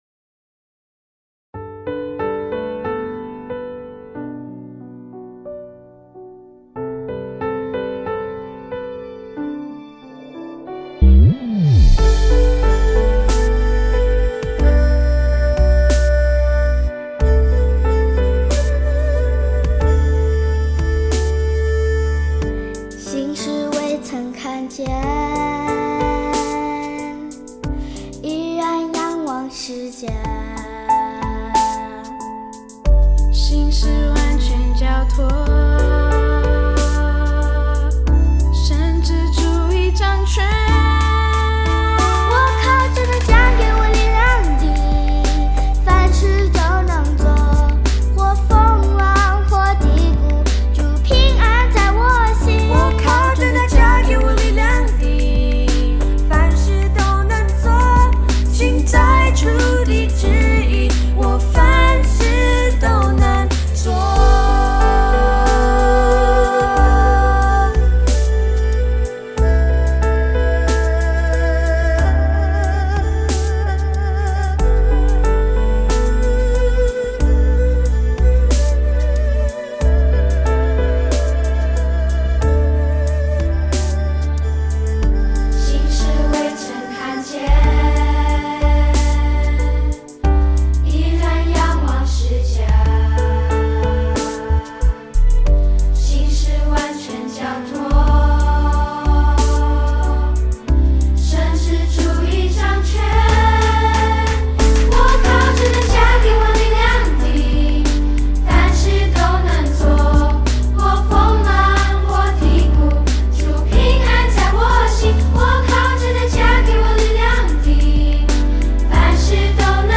动作跟唱 | 凡事都能做 (视频+音频)